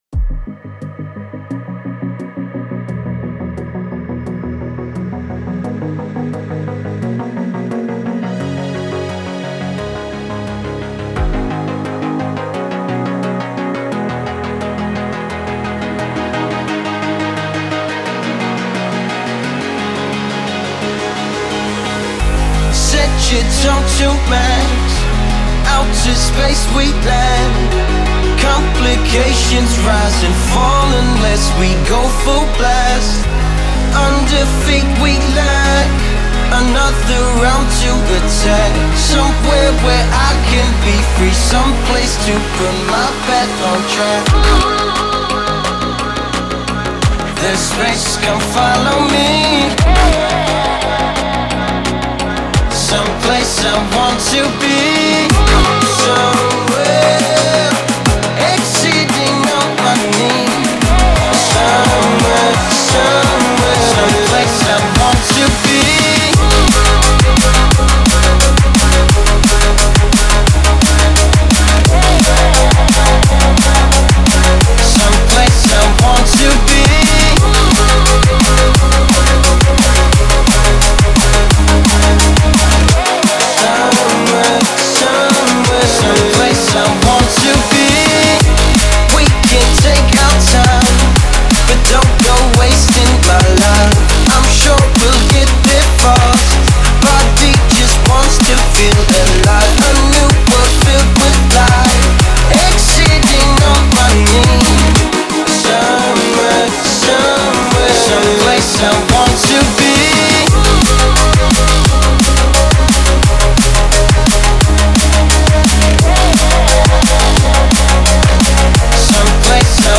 BPM174-174
Audio QualityPerfect (High Quality)
Drum and Bass song for StepMania, ITGmania, Project Outfox
Full Length Song (not arcade length cut)